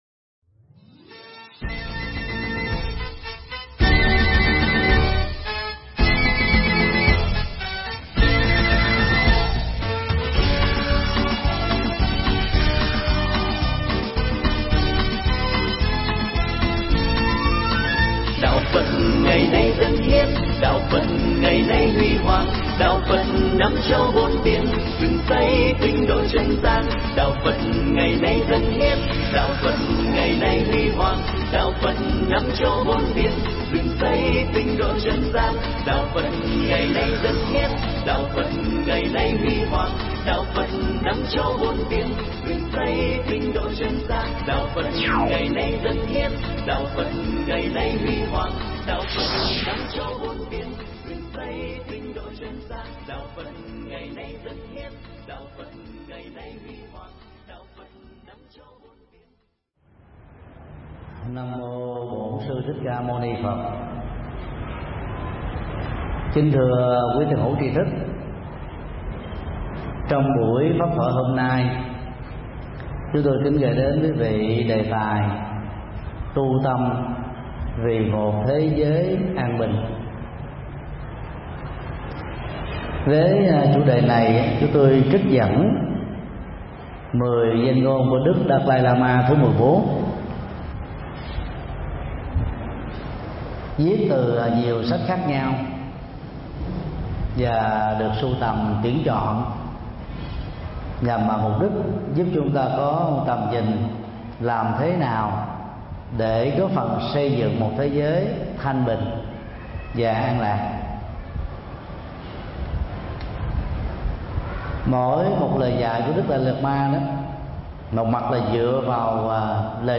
Mp3 Pháp thoại Tu Tâm Vì Một Thế Giới An Bình do thầy Thích Nhật Từ giảng tại chùa Xá Lợi 86B Bà Huyện Thanh Quan, Quận 3, TP.HCM ngày 27 tháng 07 năm 2013